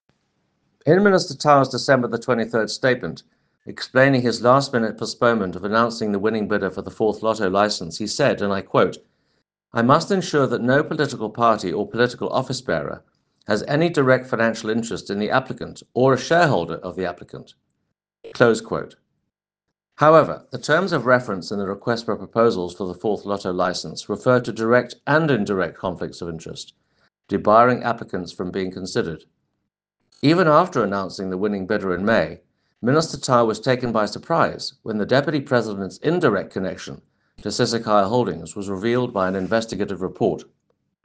Issued by Toby Chance MP – DA Spokesperson on Trade, Industry and Competition
soundbite by Toby Chance MP
Toby-Chance.mp3